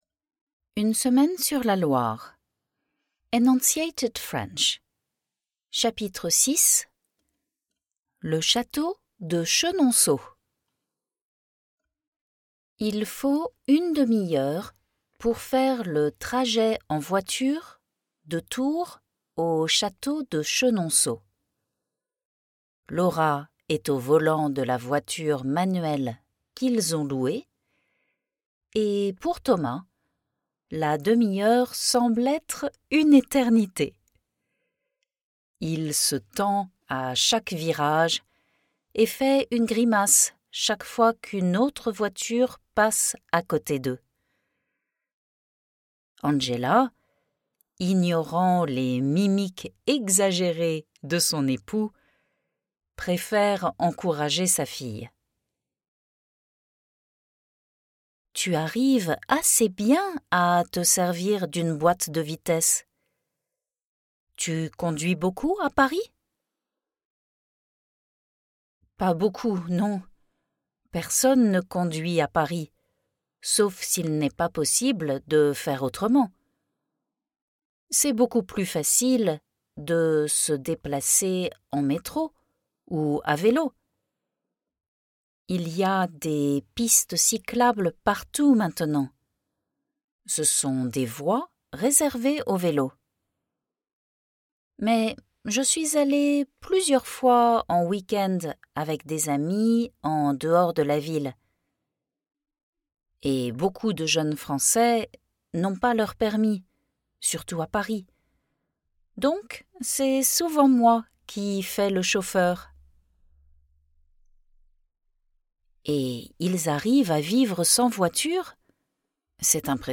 • A slower version with French glidings and elisions
8 Hours 22 Mins of story chapters + Q&A exercises – all professionally recorded by a Parisian native + 186-page transcript with English translation.
Chapter 6 – Enunciated Recording